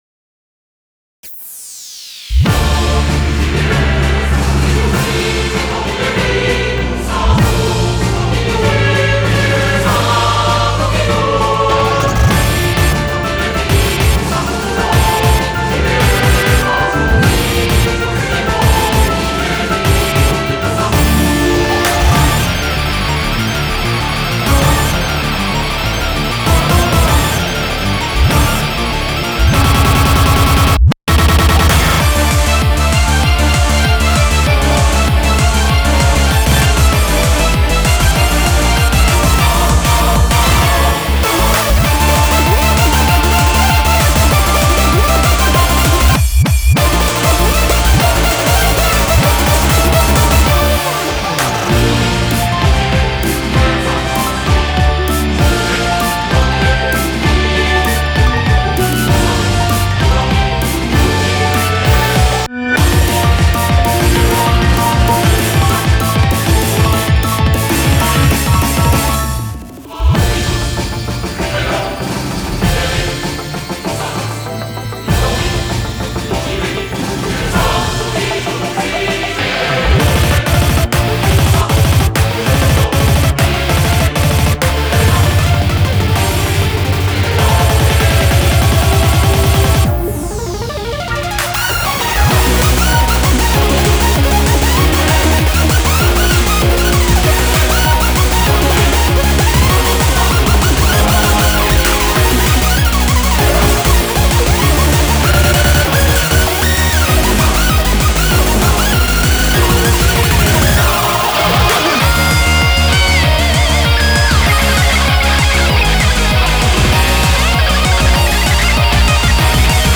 BPM98-195